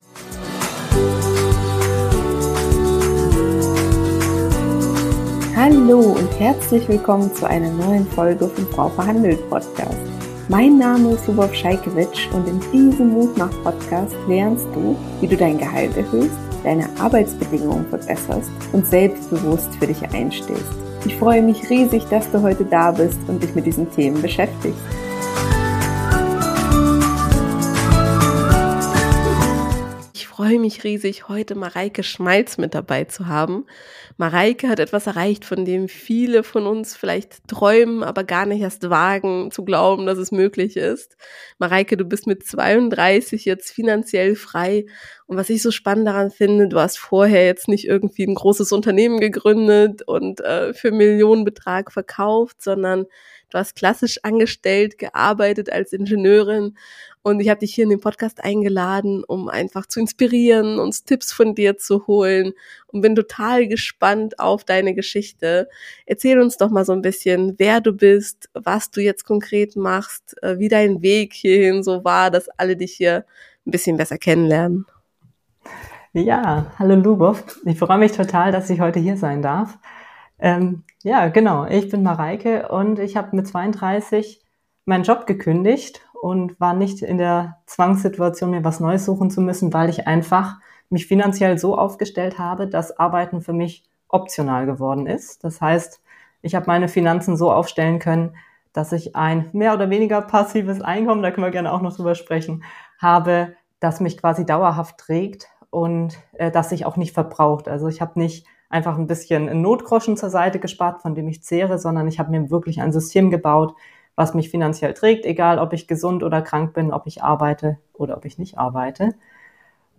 Wir gehen Schritt für Schritt durch ihre Geschichte: frühe Finanzbildung, Spar- & Investment-Strategie, clevere Gehaltsverhandlungen, Side Hustles und ihre Immobilien-Investments. Dazu gibt’s konkrete Buchtipps, Rechenbeispiele und ihre ehrlichen Learnings (inkl. Fehlern). Wenn dich finanzielle Unabhängigkeit, kluge Gehaltsverhandlungen und ein alltagstauglicher Plan interessieren, ist dieses Interview genau das Richtige für dich.